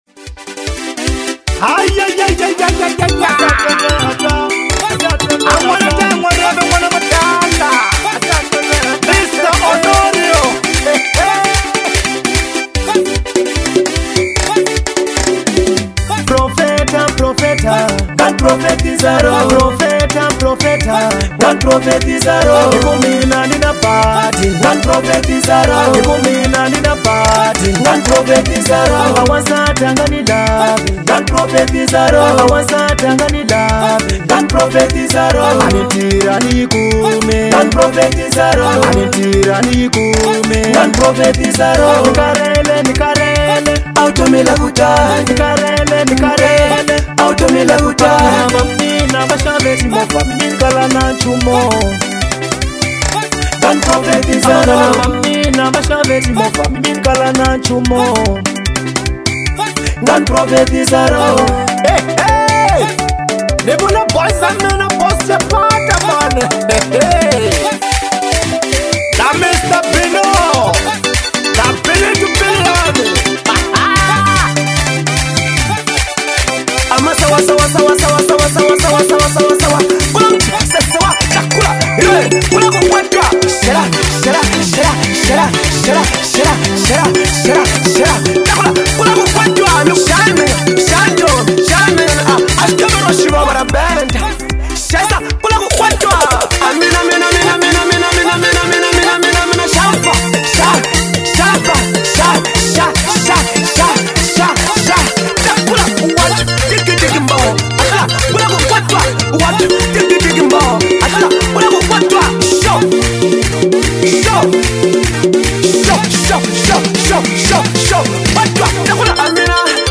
| Afro Bongo